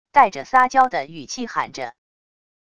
带着撒娇的语气喊着wav音频